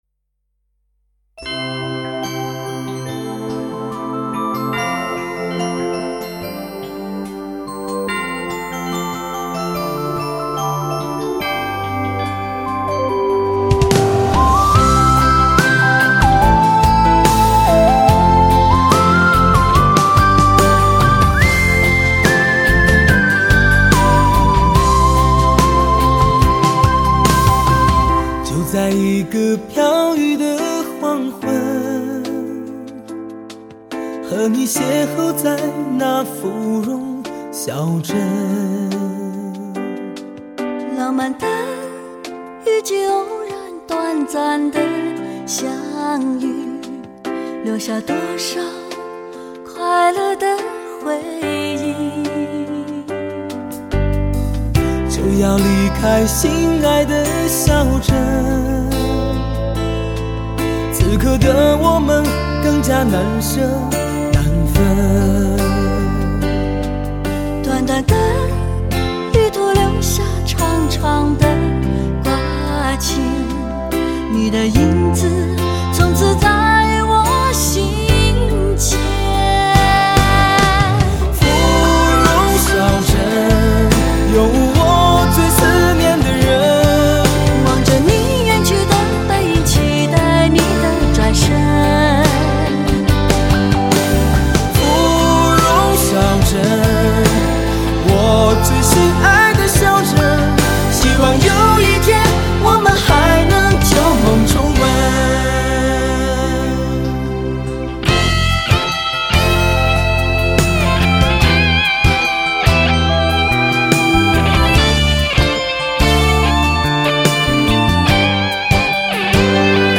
充满磁性的男声 中国流行乐坛未来的王者